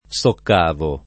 [ S okk # vo ]